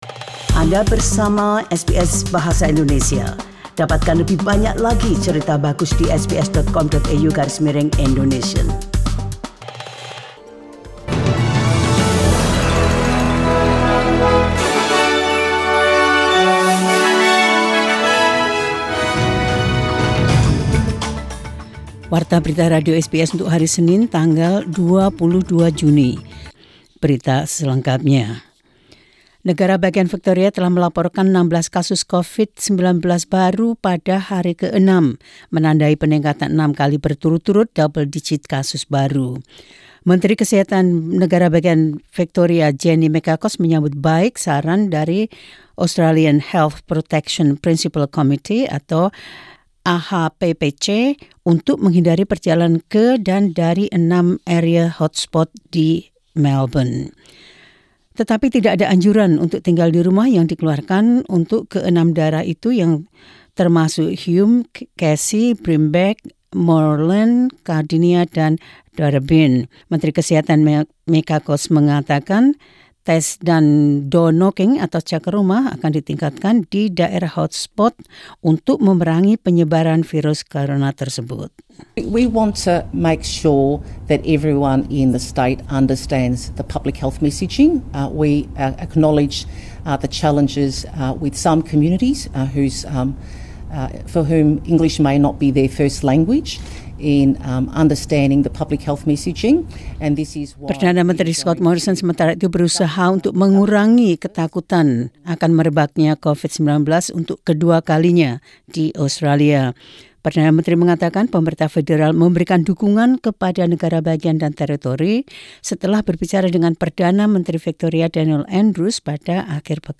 Indonesian News Indonesian Program - 22 June 2020.